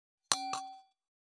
313シャンパングラス,ウィスキーグラス,ヴィンテージ,ステンレス,金物グラス,
効果音厨房/台所/レストラン/kitchen食器